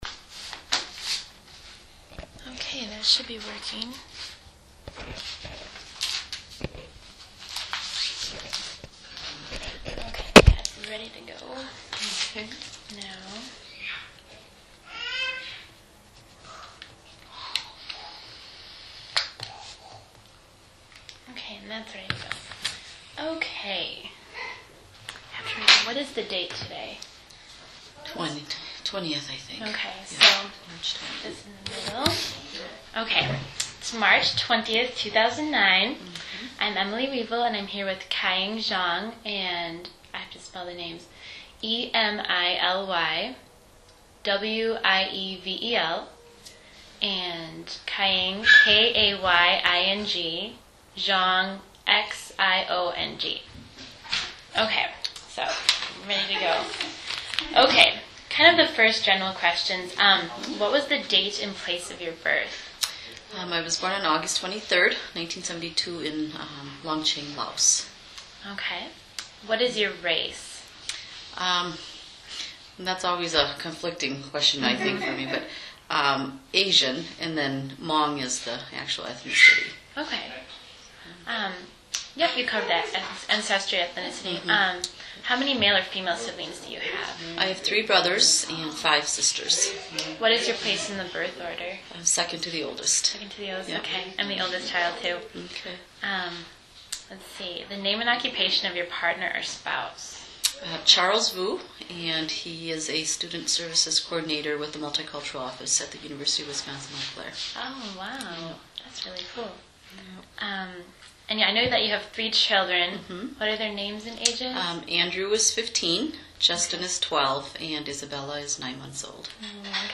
This interview is part of an oral history project conducted in honor of 25th anniversary of the Women's Studies Program at the University of Wisconsin - Eau Claire.